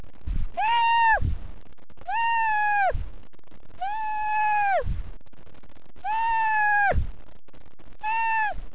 Here are a list of Accents and Impersonations I can do...
Cat's Meow
catsounds.wav